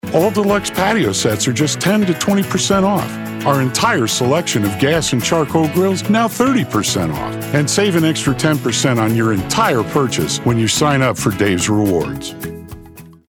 A Uniquely Deep, Resonant and Relatable Voice Over Actor
Commercials
I have a complete home studio with a RØDE NT1 5th Generation Large-Diaphragm Studio Condenser Microphone, a FocusRite 4th Generation 2i2 Audio Interface.